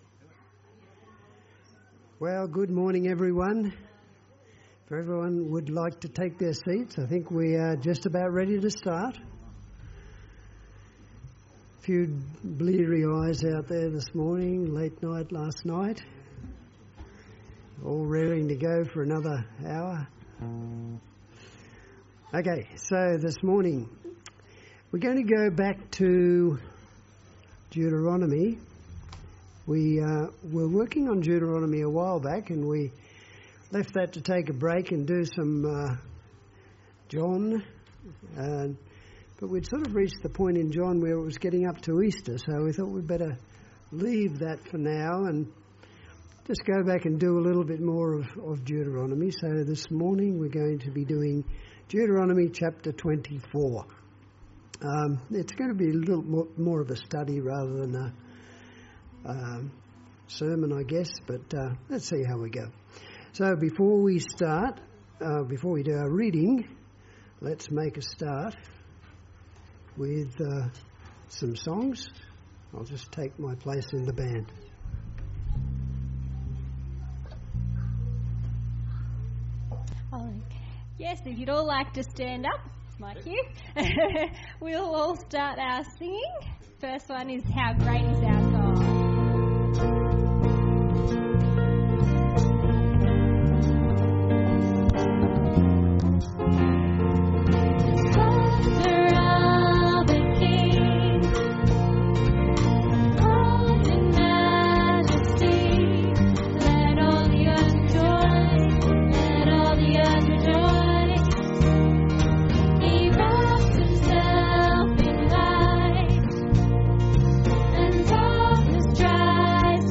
Service Type: Sunday Church Download Files Notes Topics: Gleaning , Hired help , Leprosy , marriage , Pledges « 7s and 70s Deuteronomy 25 »